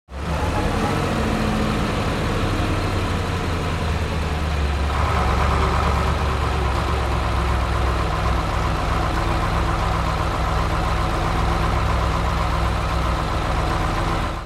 دانلود آهنگ کامیون 11 از افکت صوتی حمل و نقل
جلوه های صوتی
دانلود صدای کامیون 11 از ساعد نیوز با لینک مستقیم و کیفیت بالا